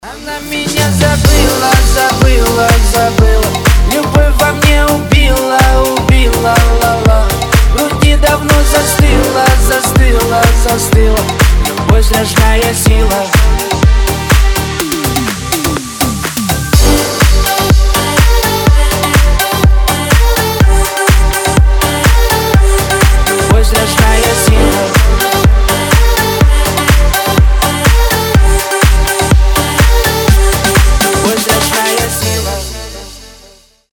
• Качество: 320, Stereo
громкие
remix
грустные
Club House
Громкая, но грустная песня про отношения